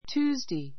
Tuesday 小 A1 tjúːzdei テュ ー ズ デイ 名詞 複 Tuesdays tjúːzdeiz テュ ー ズ デイ ズ 火曜日 ⦣ 週の第3日. on Tuesday ♔基本 on Tuesday 火曜日に Today is Tuesday.=It's Tuesday today.